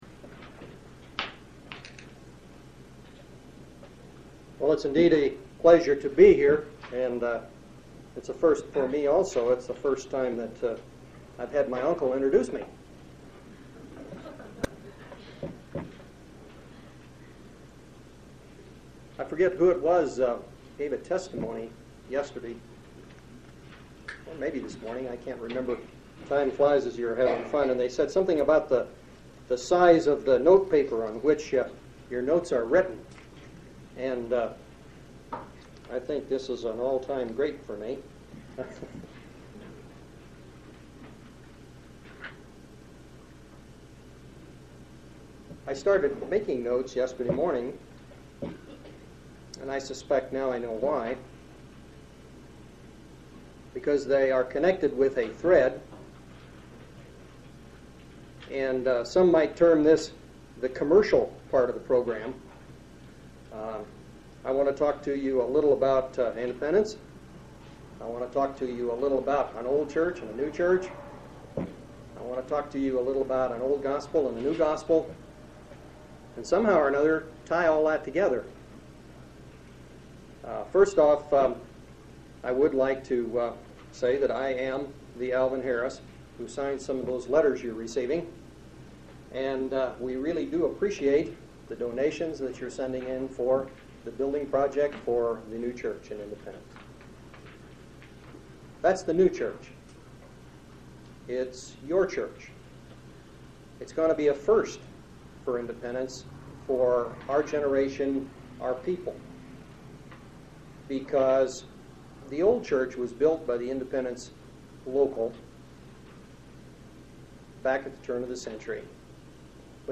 6/9/1990 Location: Colorado Reunion Event: Colorado Reunion